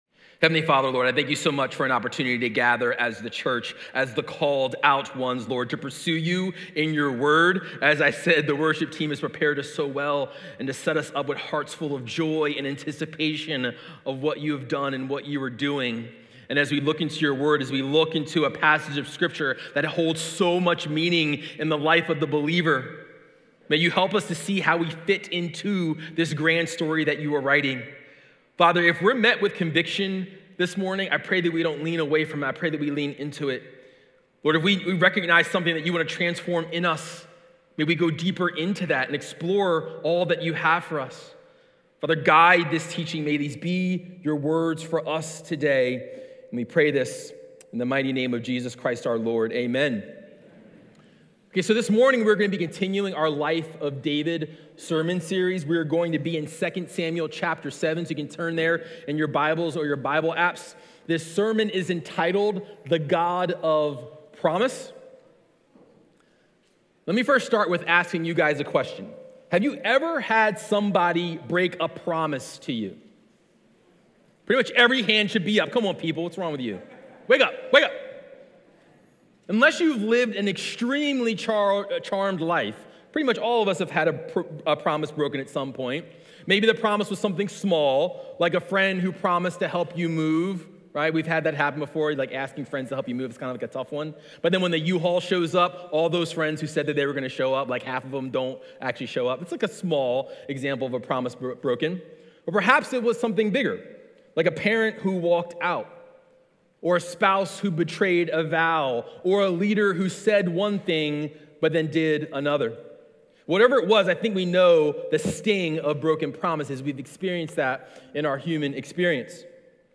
Watch previously recorded Sunday sermons.